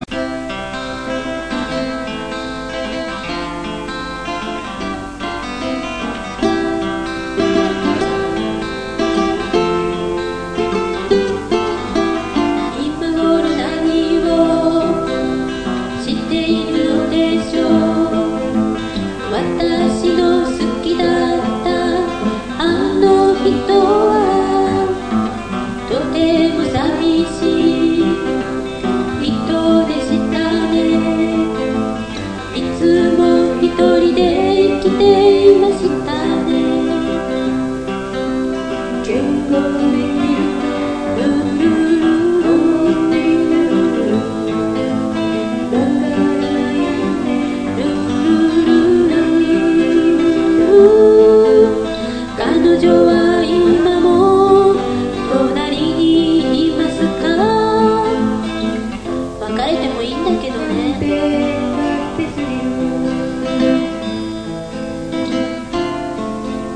ギター -オリジナル曲-
ライブ版。
バンドは男２人と女３人。
楽器はギター３台とタンバリン他で、アコースティックが中心でした。
当時のテープ録音をサウンドレコーダーで編集したので、音はモノラルです。
体育館を借りて、自分たちだけでコンサートをやりました。